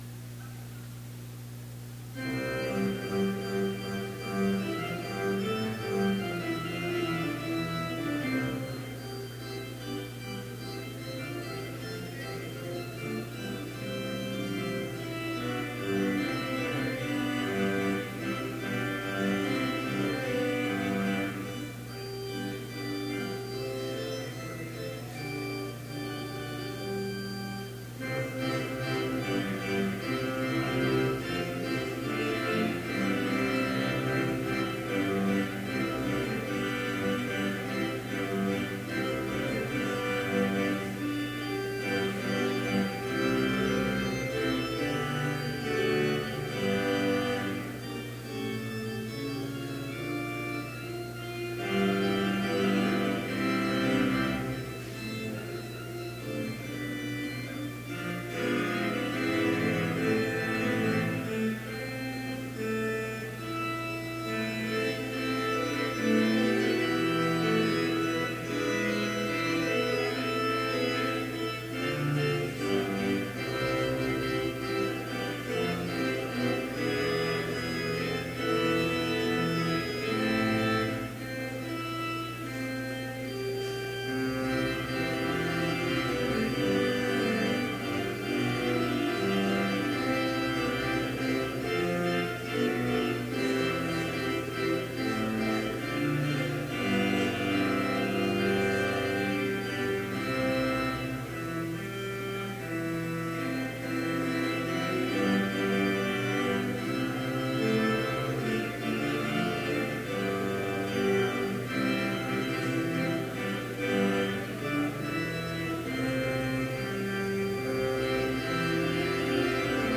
Complete service audio for Chapel - April 18, 2017